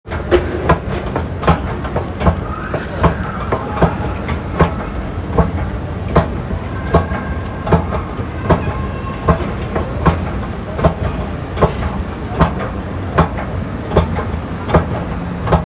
2. Escalator churning
Broadmead.m4a